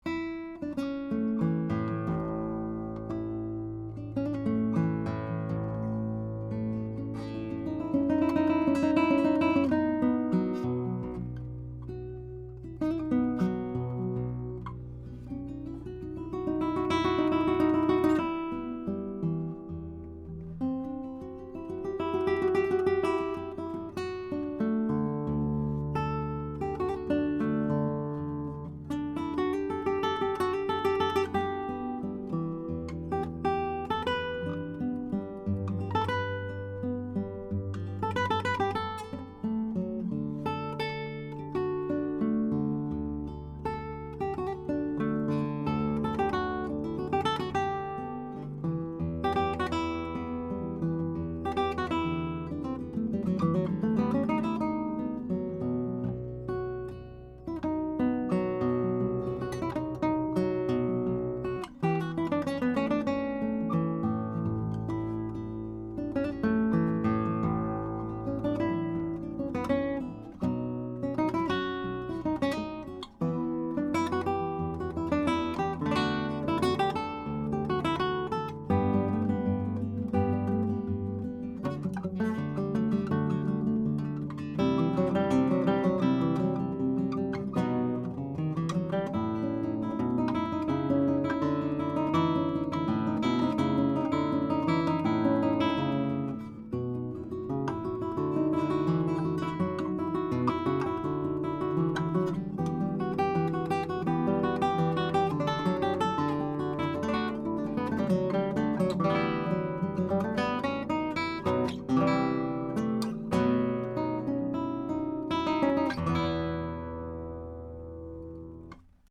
Here are 14 MP3s of the Chandler TG Channel using a Neumann TLM67 on Milagro 10-String Classical Harp Guitar into a Metric Halo ULN-8 converter, to Logic, with no additional EQ or any other effects: